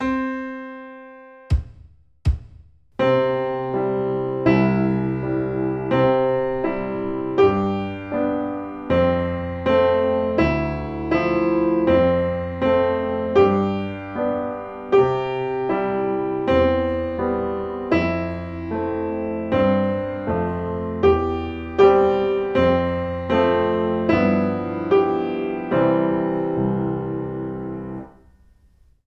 03 - Rythme frappé